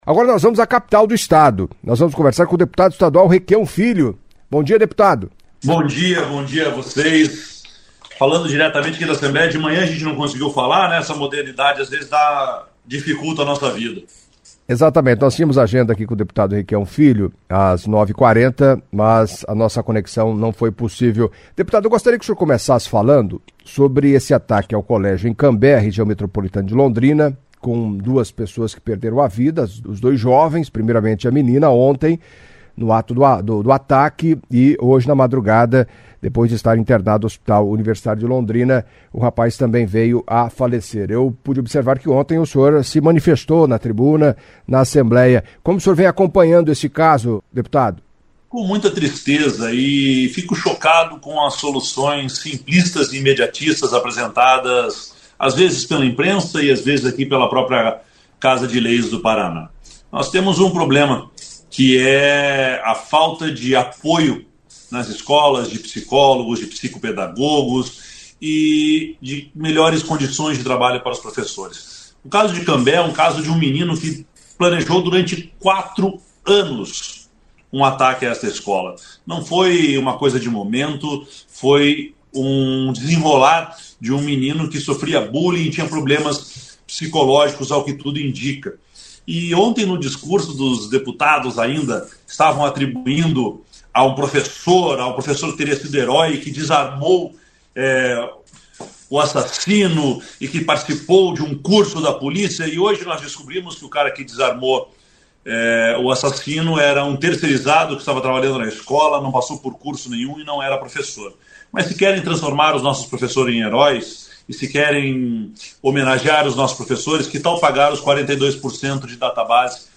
Em entrevista à CBN Cascavel nesta terça-feira (20) o deputado estadual, Requião Filho, do PT, entre outros asssuntos, falou do novo modelo de concessão de rodovias, privatização da Copel, tragédia em Cambé, e respondeu perguntas de ouvintes, acompanhe.